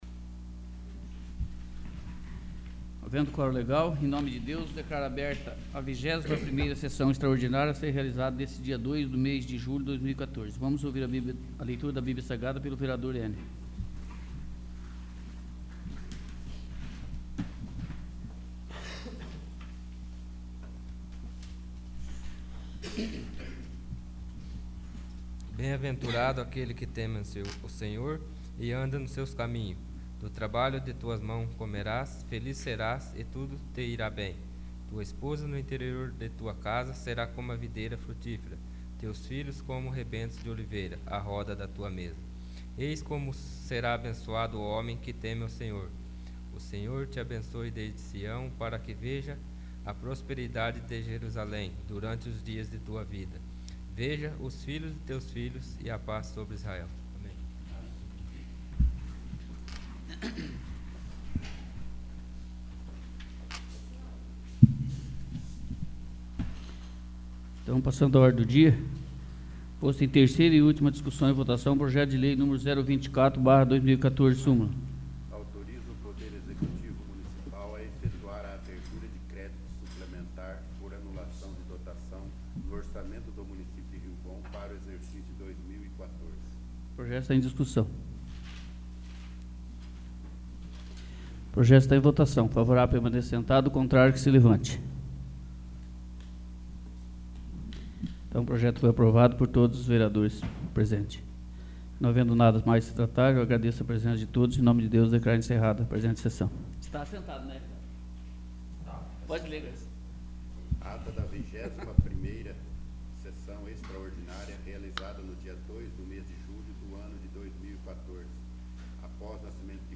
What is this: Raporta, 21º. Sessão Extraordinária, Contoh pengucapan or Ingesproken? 21º. Sessão Extraordinária